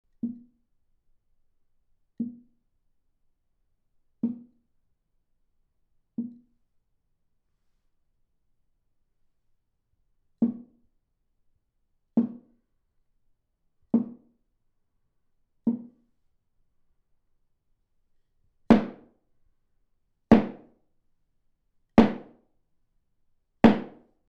Она спроектировала для Второй Композиции ударный инструмент с особым звучанием, это — закрытый со всех сторон куб размером 43 на 43 см, сделанный из плит ДСП толщиной в 2 см.
Потом выяснилось, что полый ящик из любого дерева издаёт звонкий звук, попробовали заменить материал на ДСП, звук получился глухим — таким, как нужно.
Для данной записи использовалась киянка, обитая войлоком.